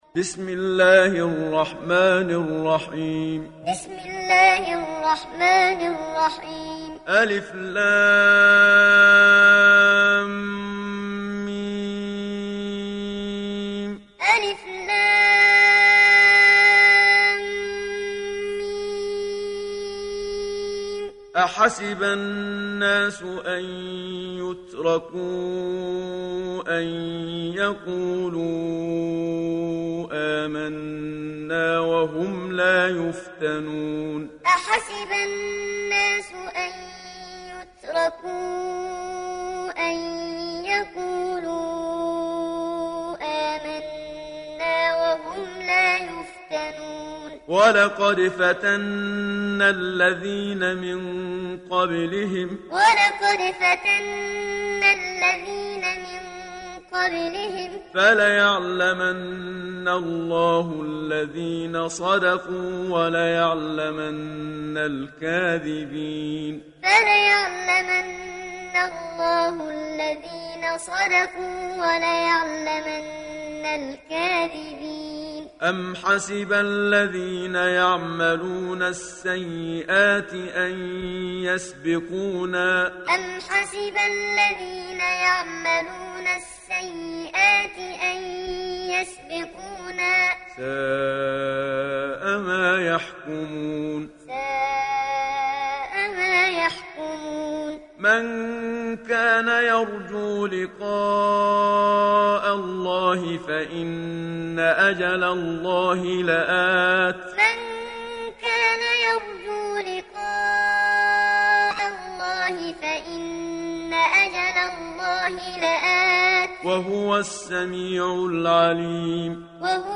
دانلود سوره العنكبوت mp3 محمد صديق المنشاوي معلم (روایت حفص)
دانلود سوره العنكبوت محمد صديق المنشاوي معلم